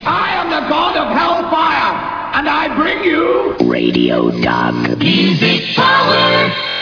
The jingles